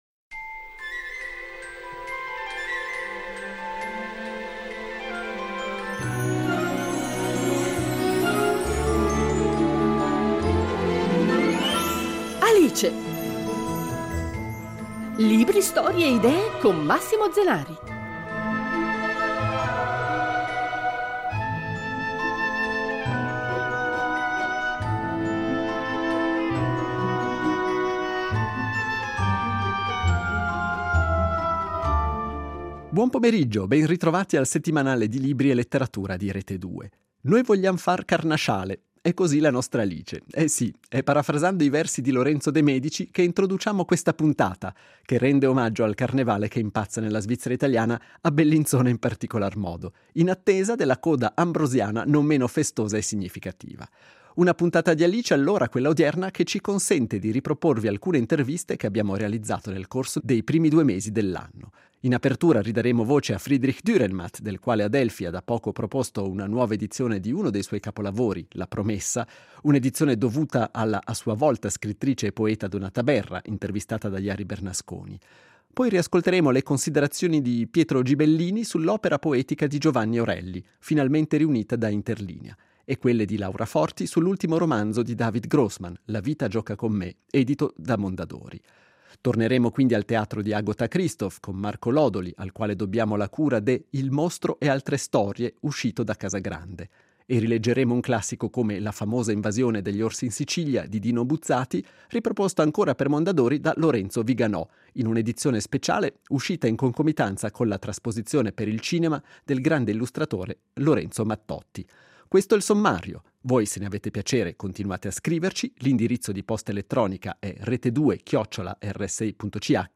Così, questa puntata del programma che Rete Due dedica ai libri e alla letteratura varrà quale occasione per riascoltare alcune interviste andate in onda nel corso dei primi due mesi dell’anno.